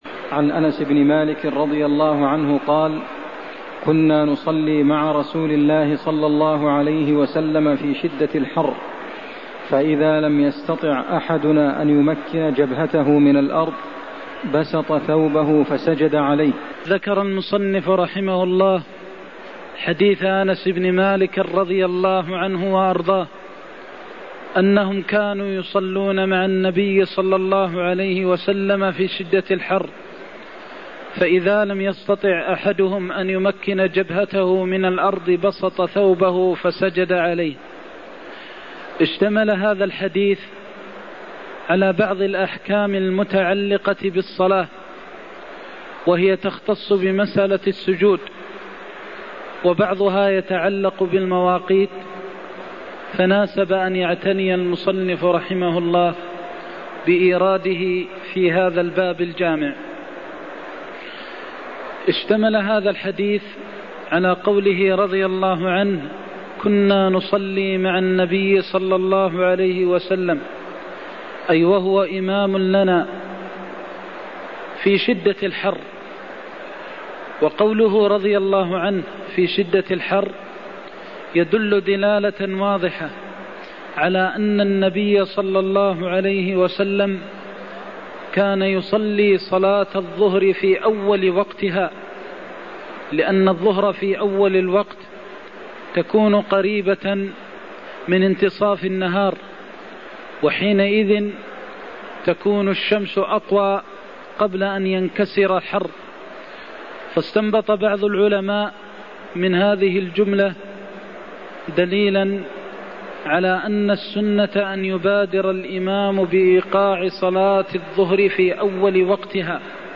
المكان: المسجد النبوي الشيخ: فضيلة الشيخ د. محمد بن محمد المختار فضيلة الشيخ د. محمد بن محمد المختار بسط الثوب في الصلاة (111)